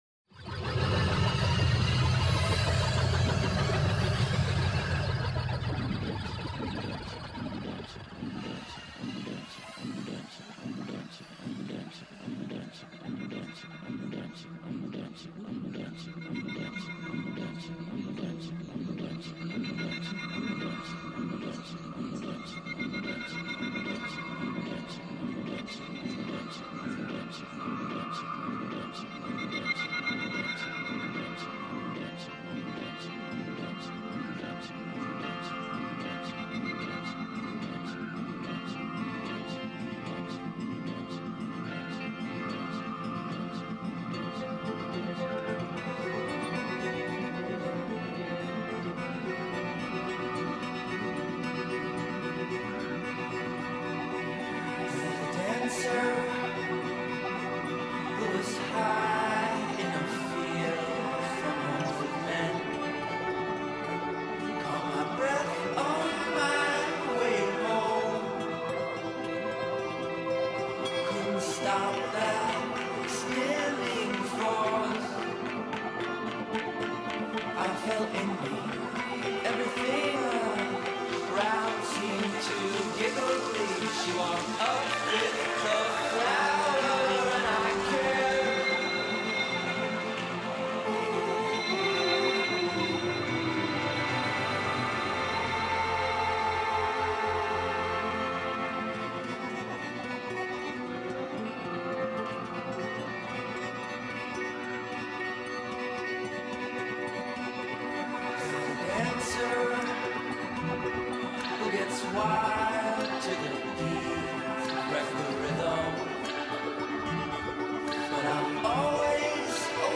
It’s been awhile since I have done one of these so I apologize for the fact my voice is a bit low on this.